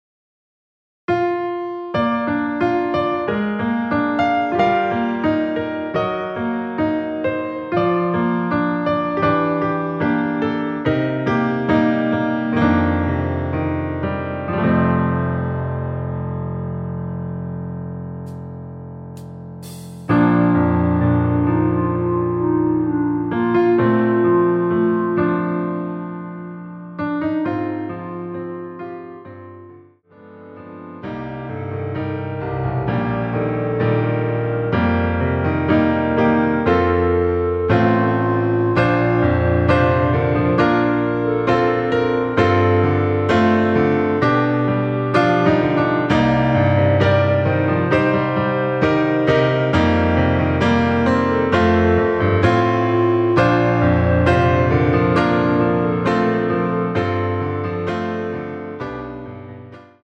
원키에서(-4) 내린 멜로디 포함된 MR 입니다.(미리듣기 참조)
Bb
멜로디 MR이라고 합니다.
앞부분30초, 뒷부분30초씩 편집해서 올려 드리고 있습니다.
중간에 음이 끈어지고 다시 나오는 이유는